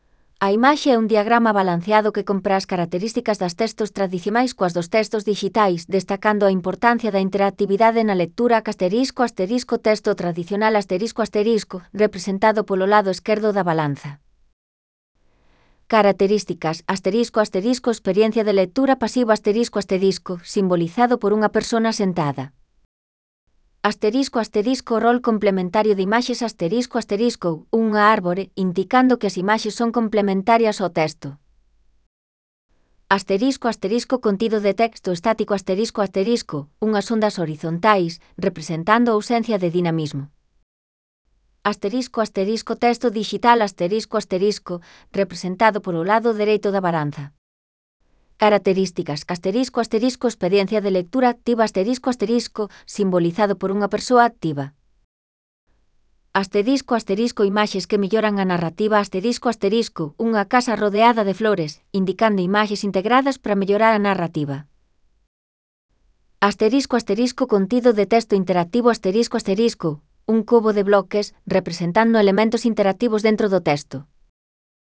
Audio con la descripción de la imagen